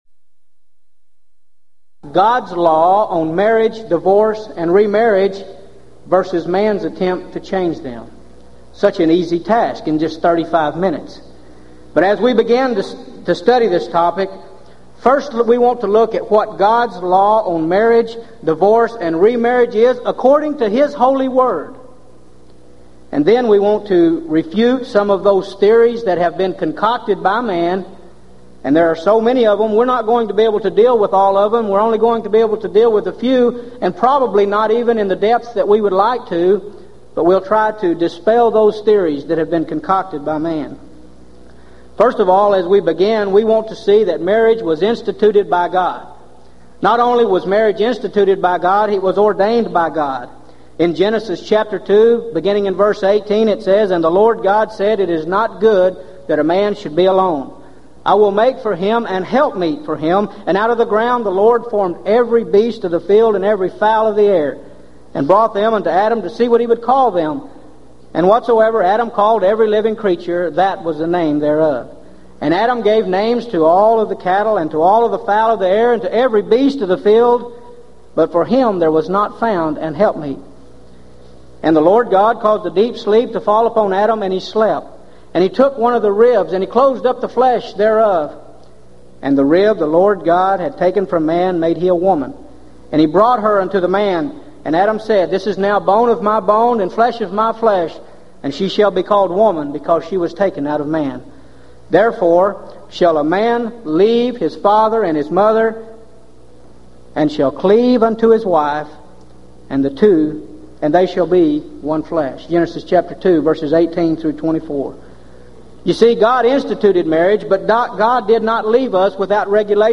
Event: 1996 Gulf Coast Lectures
If you would like to order audio or video copies of this lecture, please contact our office and reference asset: 1996GulfCoast02